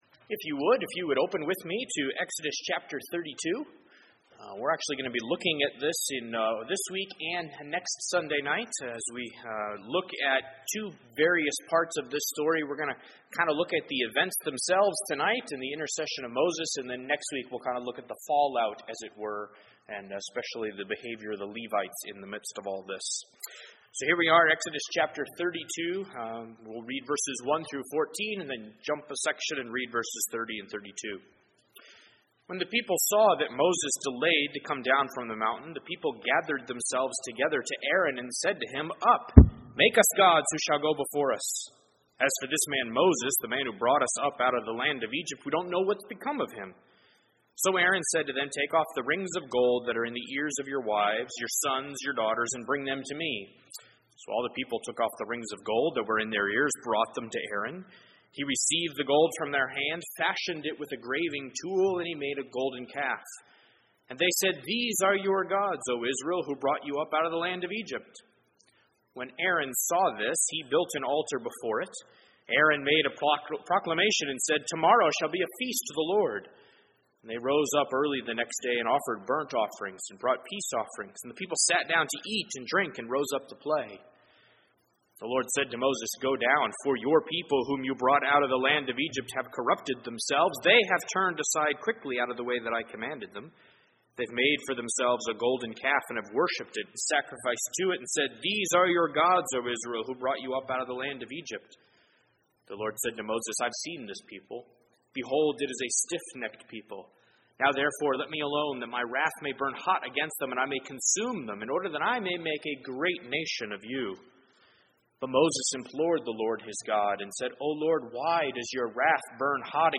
Exodus 32:30-35 Service Type: Sunday Evening %todo_render% « Exodus 32